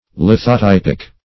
Lithotypic \Lith`o*typ"ic\, a.